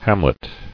[ham·let]